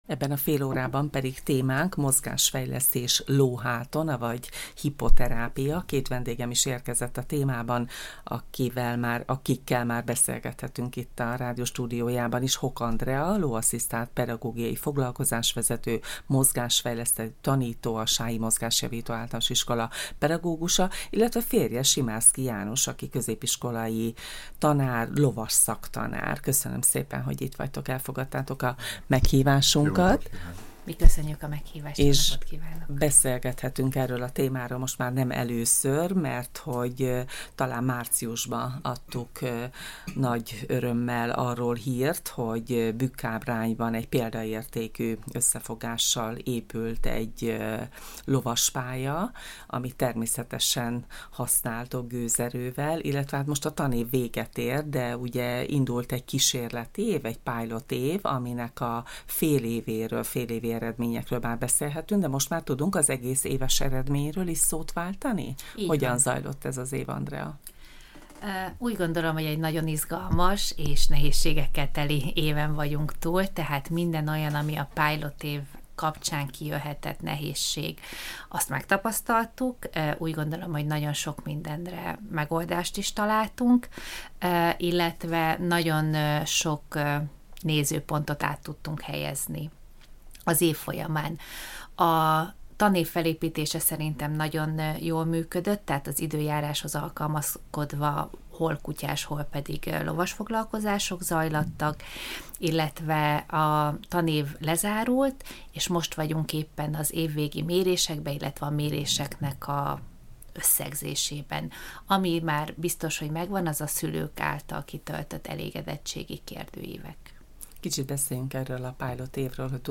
Egészség- és sportrádió